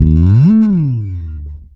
-MM SLIDE7.wav